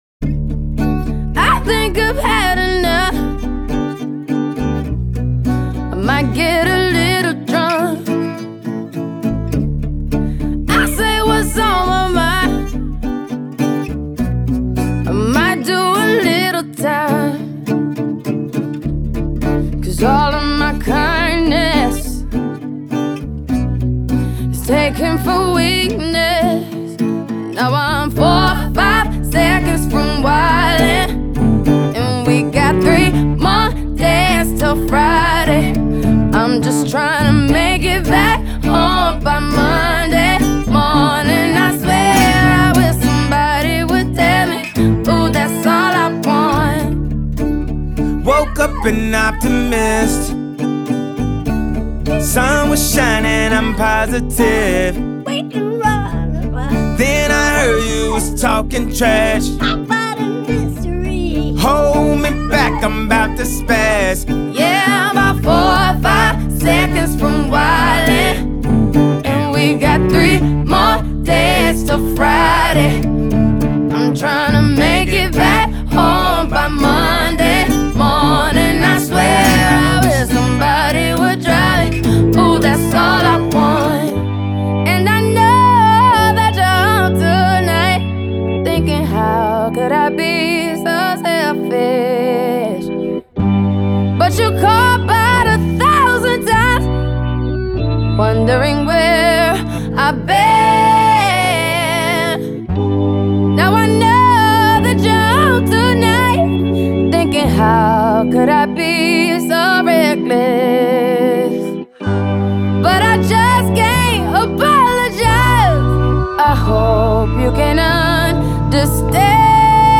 guitar
organ